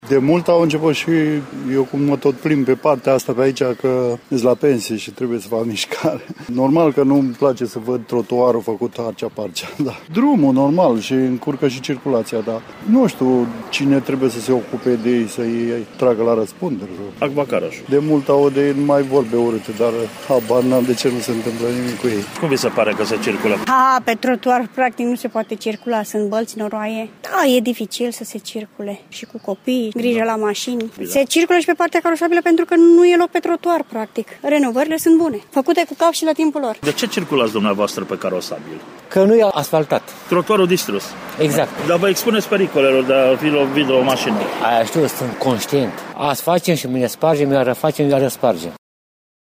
Ascultaţi ce au declarat câţiva oameni care au ciculat pe str. Făgăraşului:
Vox-Fagarasuluiu.mp3